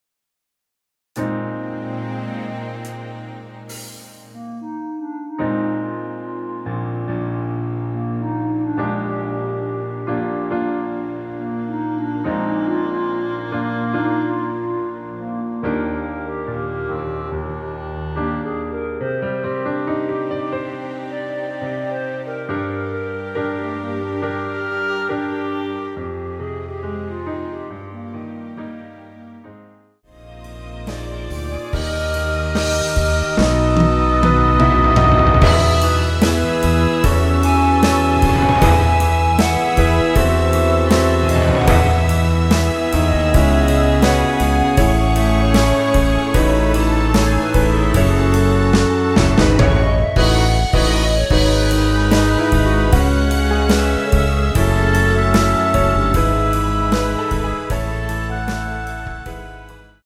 Eb
노래방에서 노래를 부르실때 노래 부분에 가이드 멜로디가 따라 나와서
앞부분30초, 뒷부분30초씩 편집해서 올려 드리고 있습니다.
중간에 음이 끈어지고 다시 나오는 이유는
뮤지컬